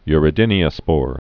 (yrĭ-dĭnē-ə-spôr) also u·re·do·spore (y-rēdə-)